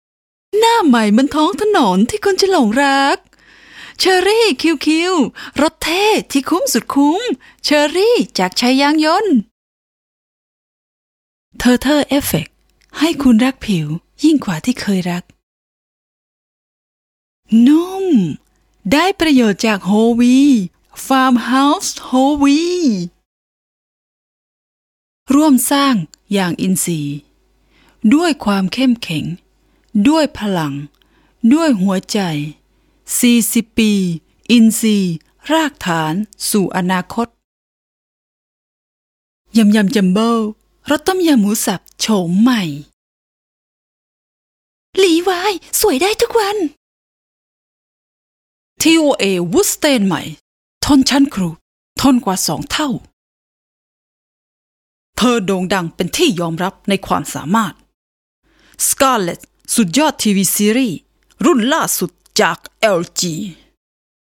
Tayca Seslendirme
Kadın Ses